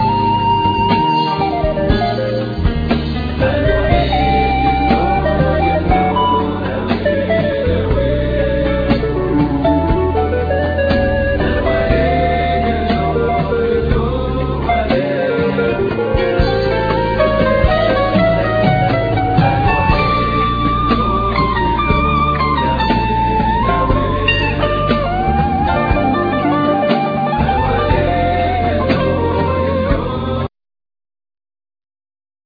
Lead Vocal,Drums,Percussion,Gumbri
Banjo,Mandola,Background vocals
Soprano & Tenor Saxophone